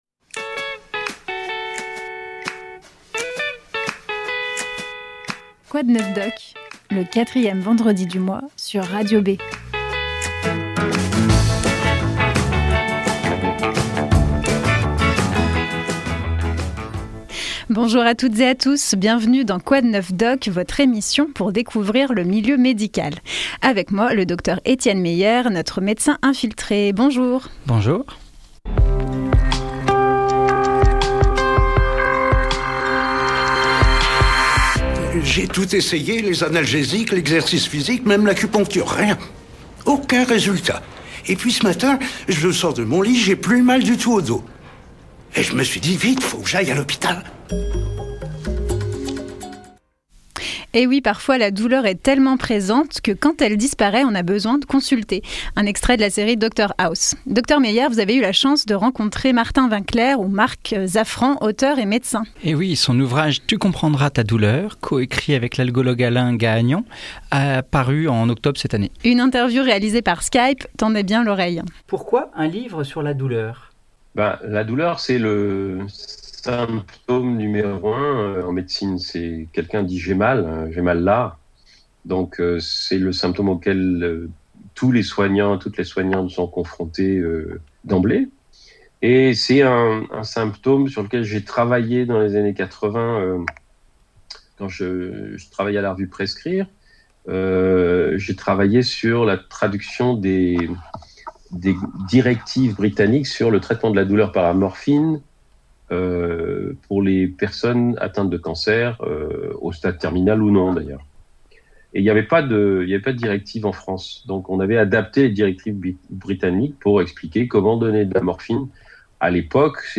Radio B, la radio locale de Bourg-en-Bresse et des Pays de l'Ain